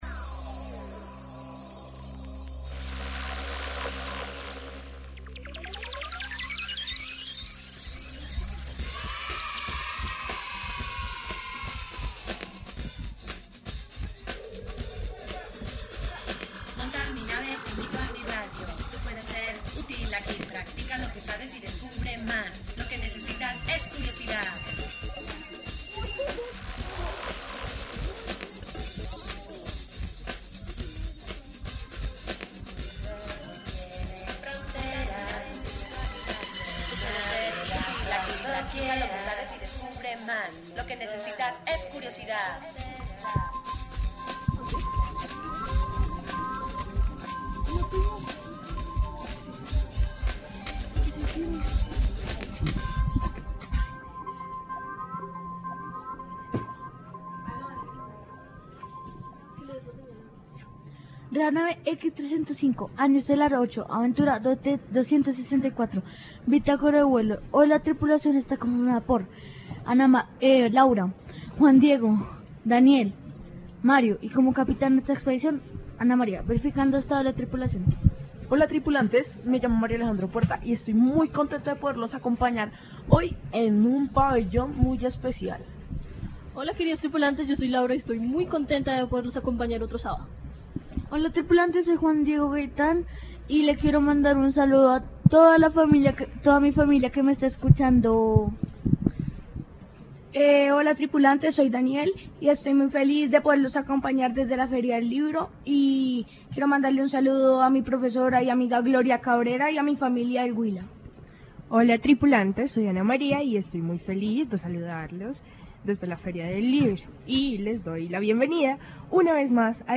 The program is broadcast from the Bogotá International Book Fair, commemorating the one-year anniversary of Gabriel García Márquez's death.